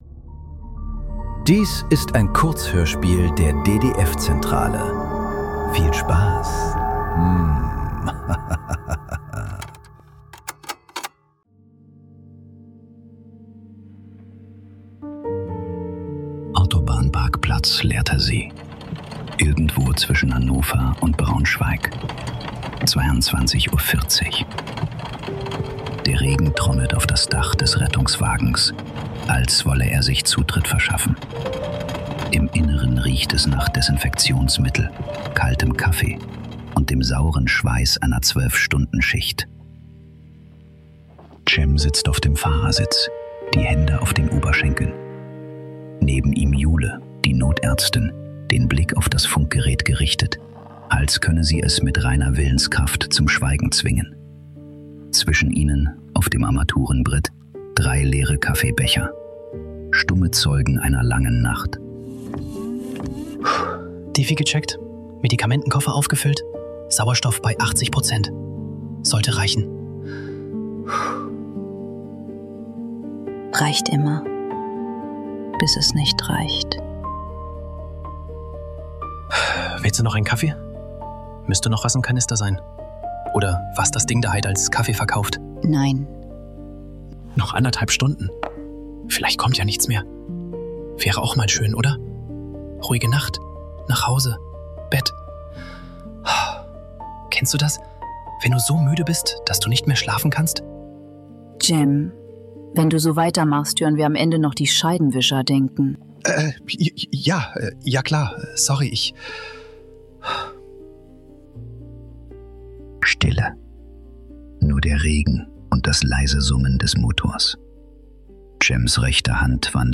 Kurzhörspiele. Leise.